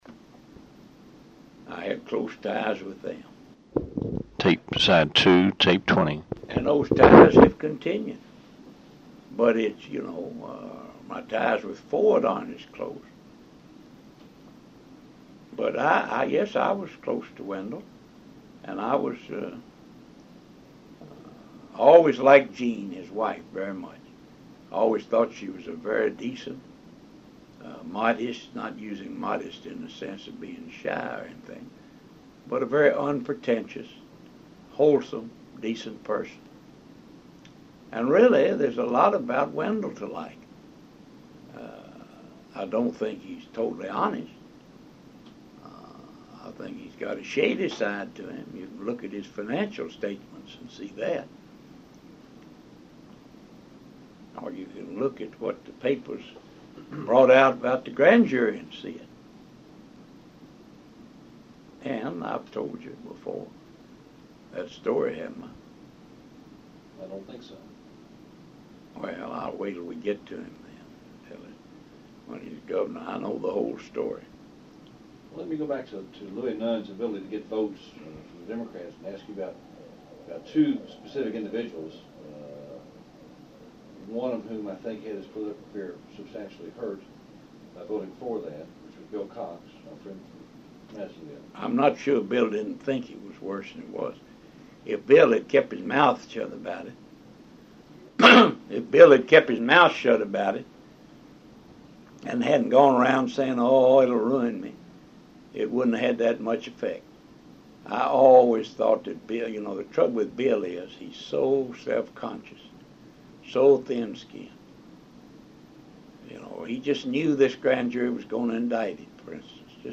Oral History Interview with Edward F. Prichard, Jr., August 2, 1983 Part 2